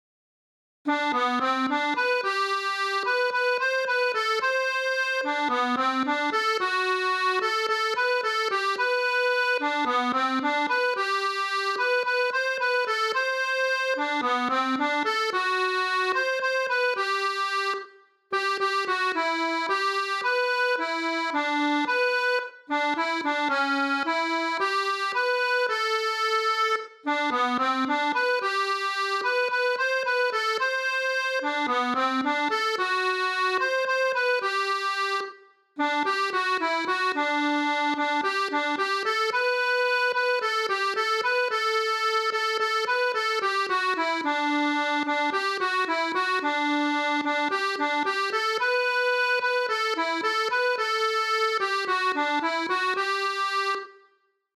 Chants de marins